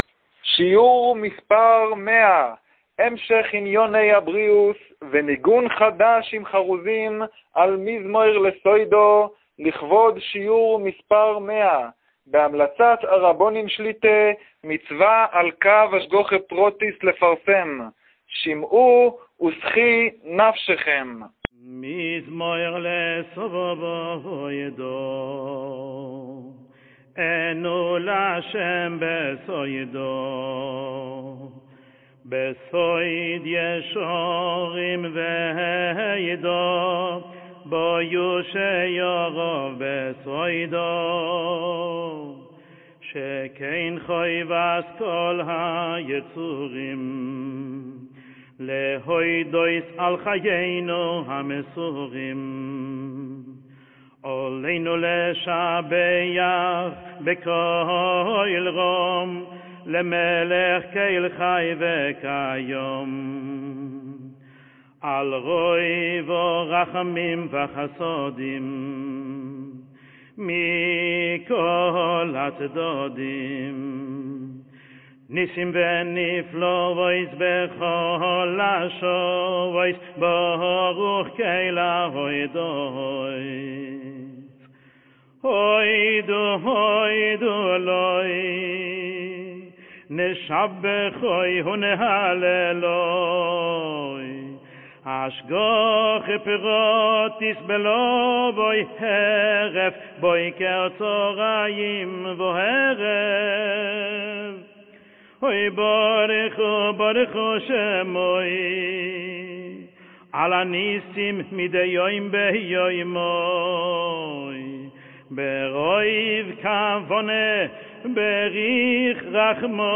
שיעור 100